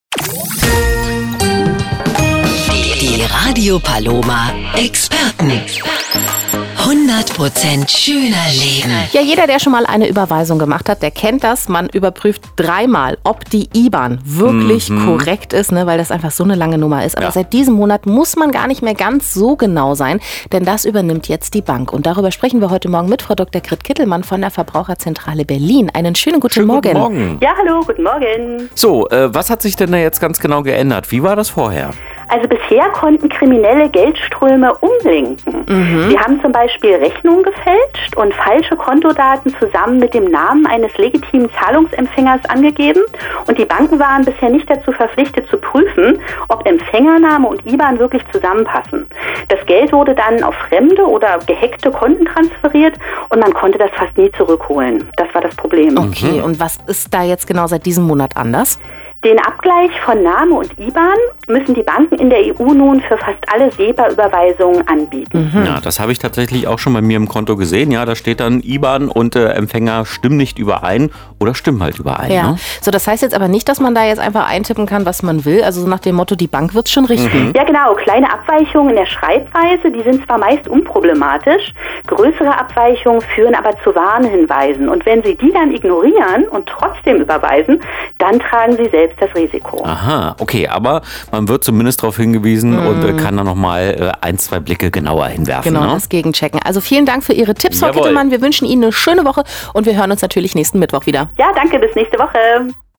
rp-verbraucherexpertin-uberweisungen.mp3